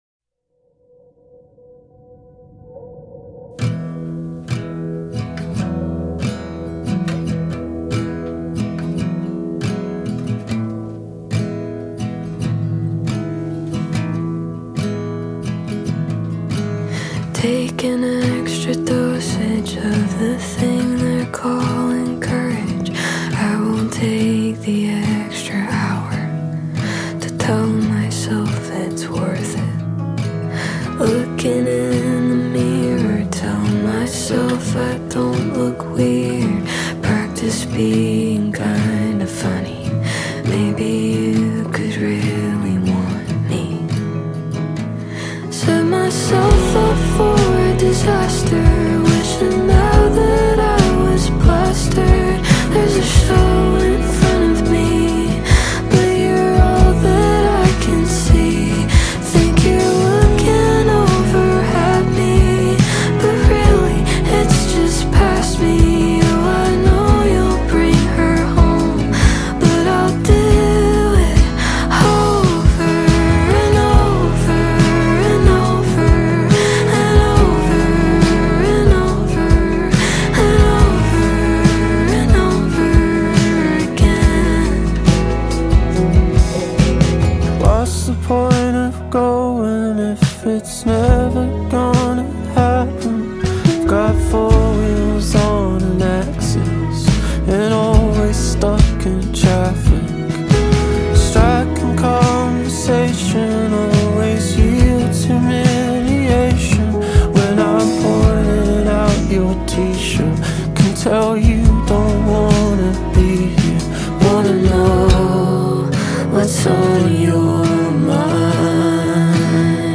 smooth vocals
rich production